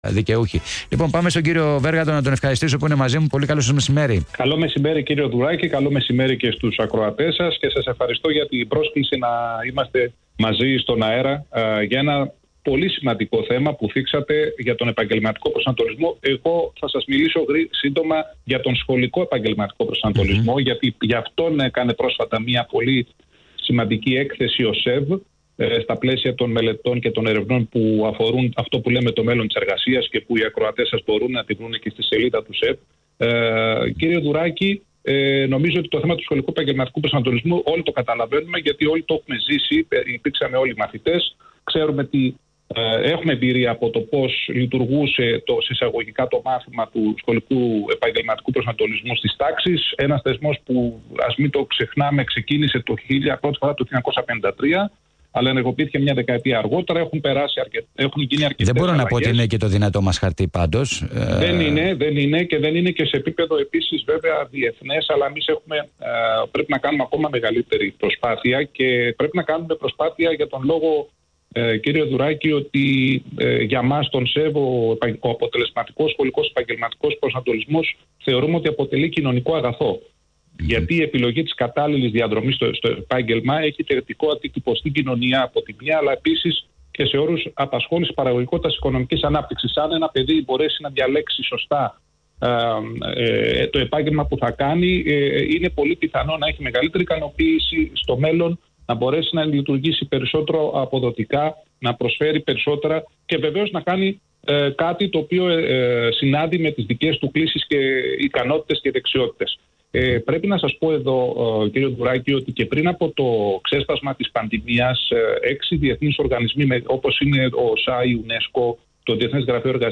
Συνέντευξη
στο Δελτίο Ειδήσεων του Ρ/Σ ALPHA 989